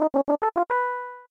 level_complete.mp3